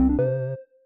Add sound effects for time items and early exit
exit.ogg